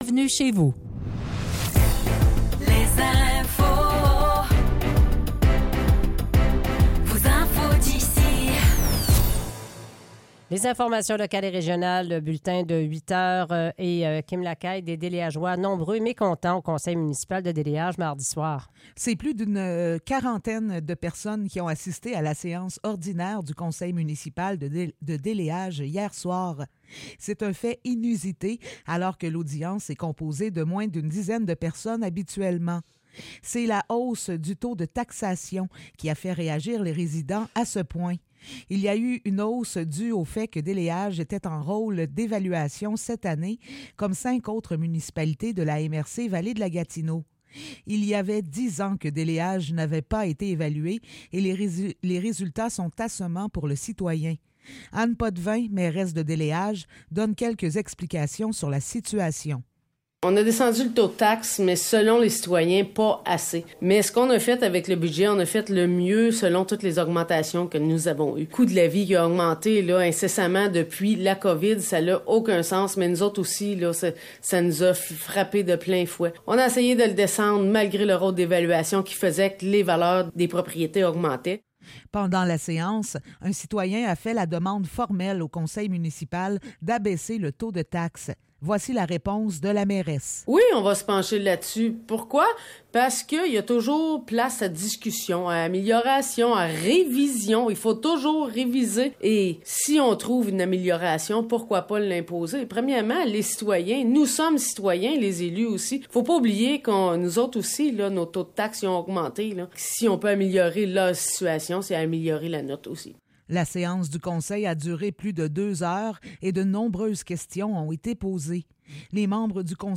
Nouvelles locales - 6 mars 2024 - 8 h